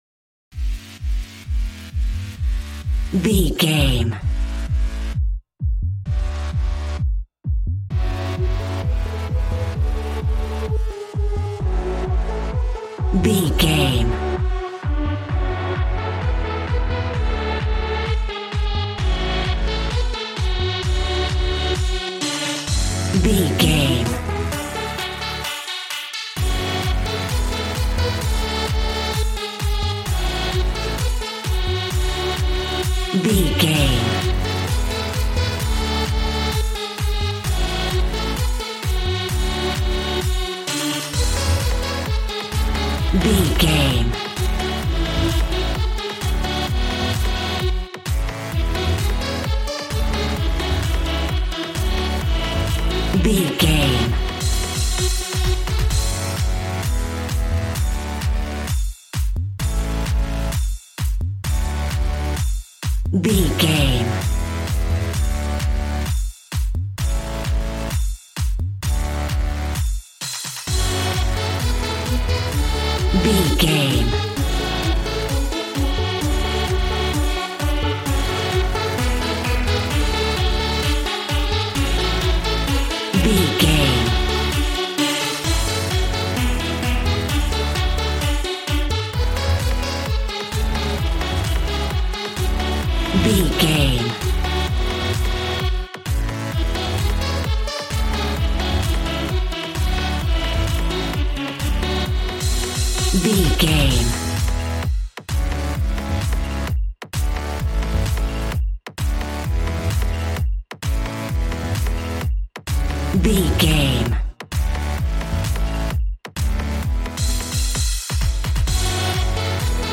Aeolian/Minor
G♭
Fast
uplifting
lively
groovy
synthesiser
drums